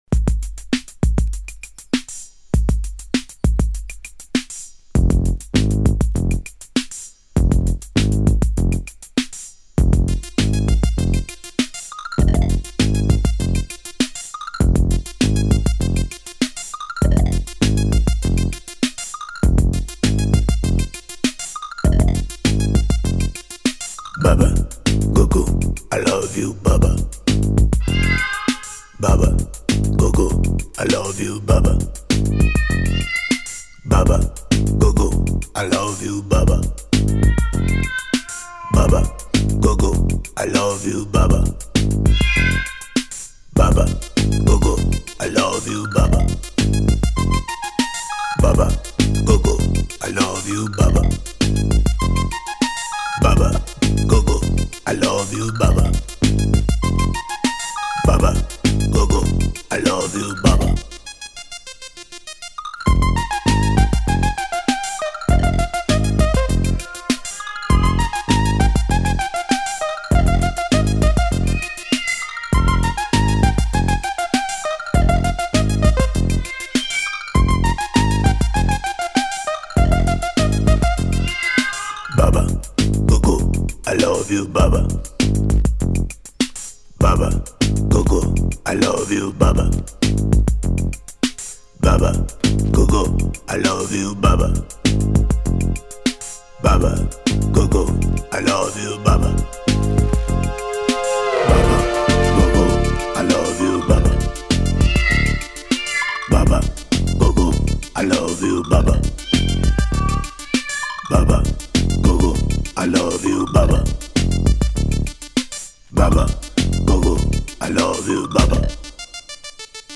du pré-skwee